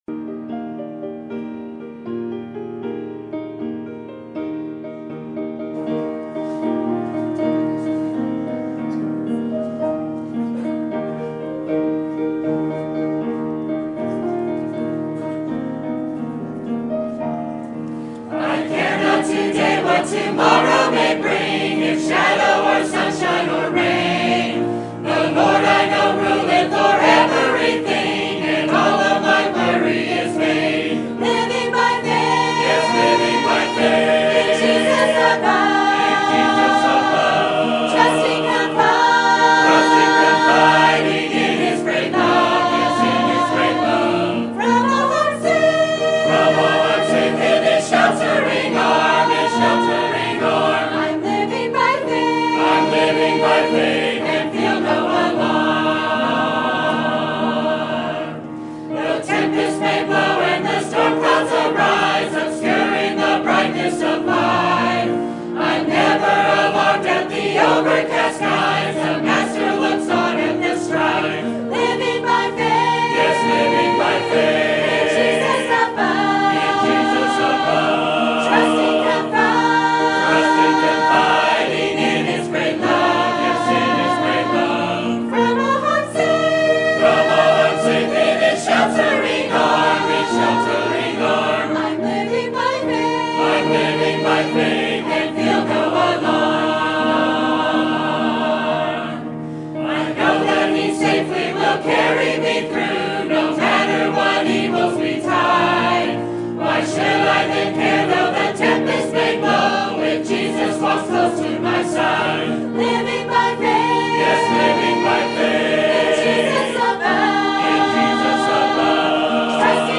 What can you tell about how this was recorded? Sermon Topic: Missions Conference Sermon Type: Special Sermon Audio: Sermon download: Download (23.86 MB) Sermon Tags: Acts Idol Missions Gospel